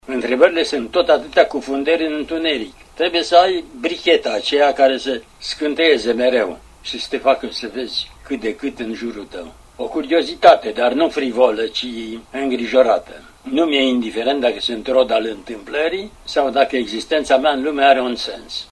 Într-o intervenţie la Radio România Cultural, după ce împlinise 106 ani, Mihai Şora încă îşi punea întrebări: ”…nu mi-e indiferent dacă sunt rod al întâmplării sau dacă existența mea în lume are un sens…”